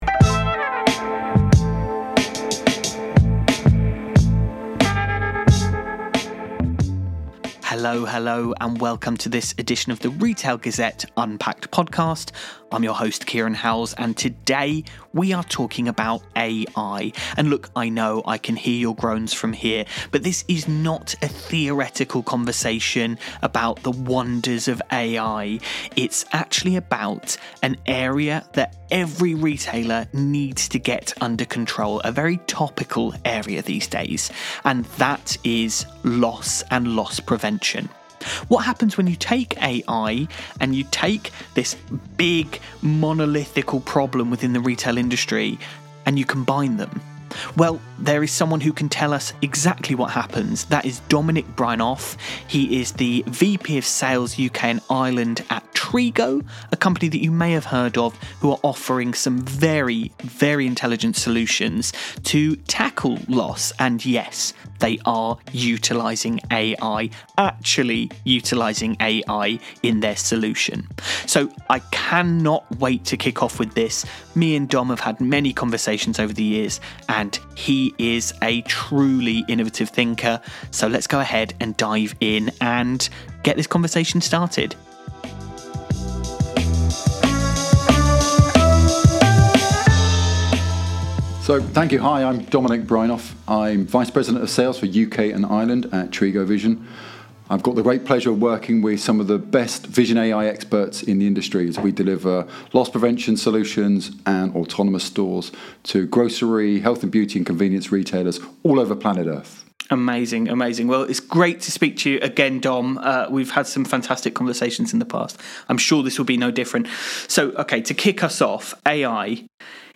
The conversation centres on the intersection of a worsening loss problem and the growing potential of AI-powered retail technology.